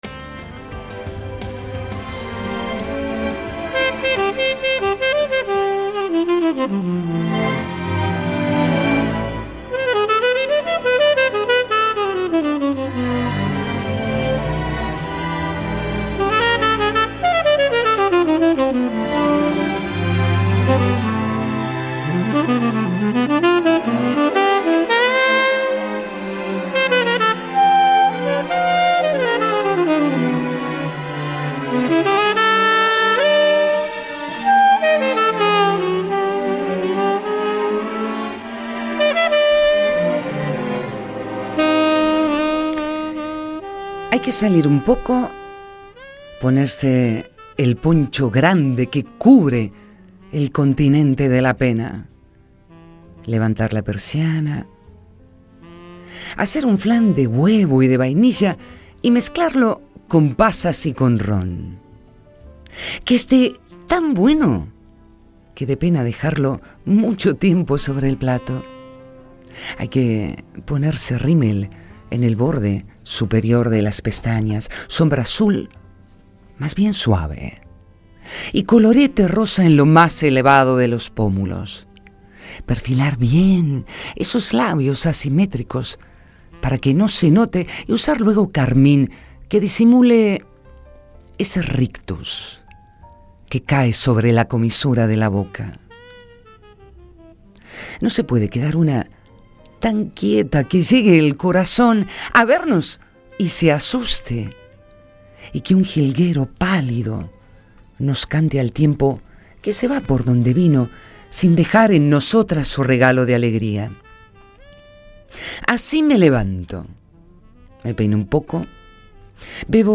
Lectura
Estando en tiempos de "trasvasarnos" de un año a otro, llenamos el espacio de La Fiaca con música y poesía.